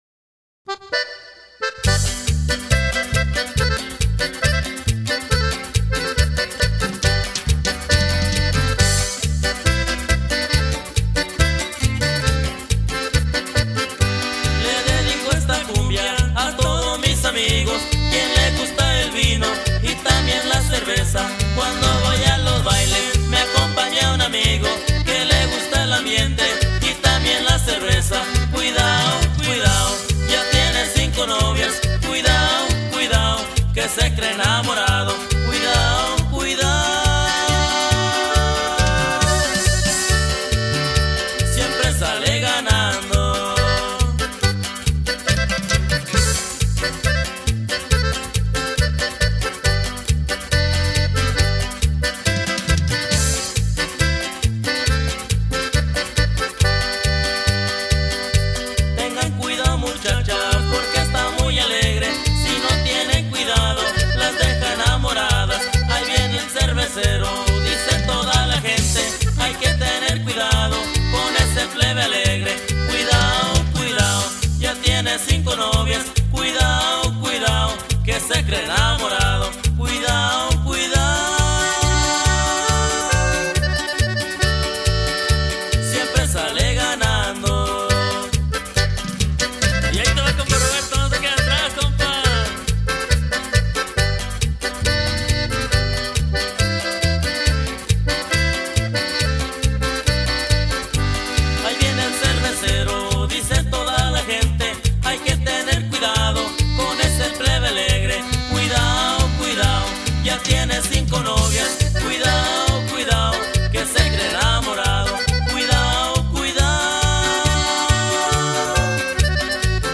Conjunto Norteño